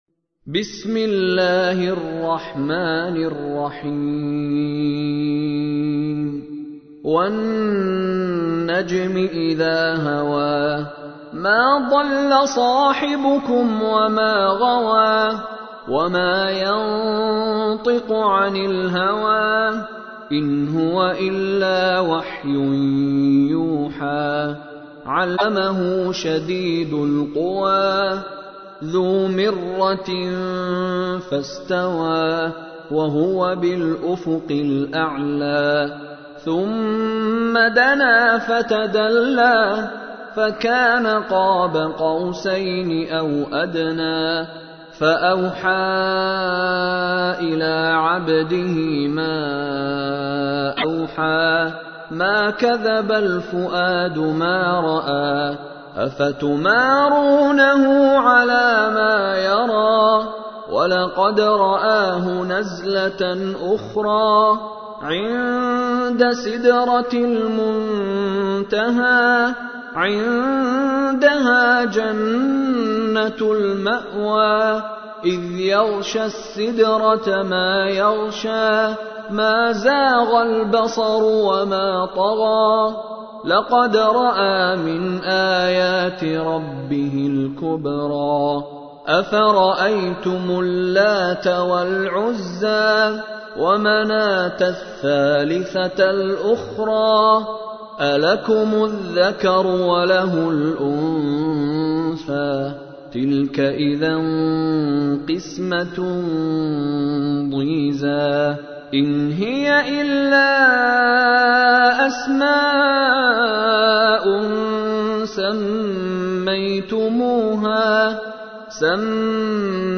تحميل : 53. سورة النجم / القارئ مشاري راشد العفاسي / القرآن الكريم / موقع يا حسين